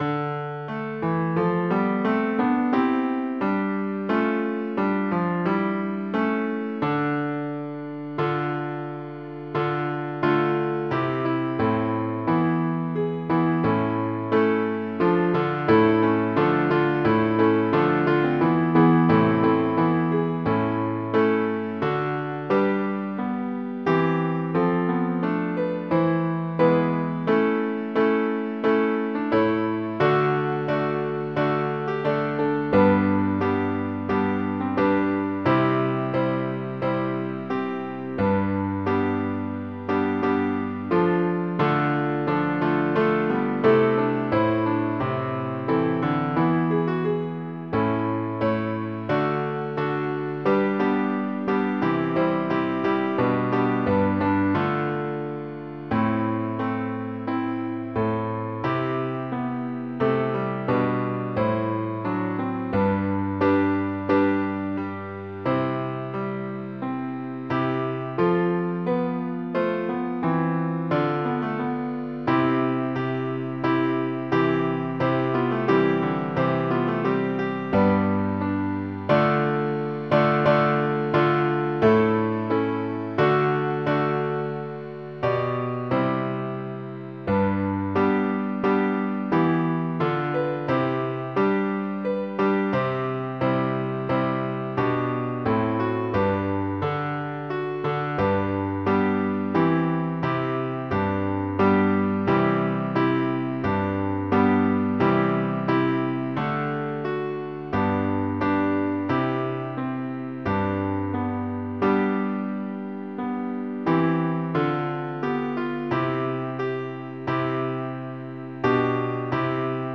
Title: Salve Regina Composer: Antonio Cifra Lyricist: Number of voices: 8vv Voicing: SATB.SATB Genre: Sacred, Motet
Language: Latin Instruments: Organ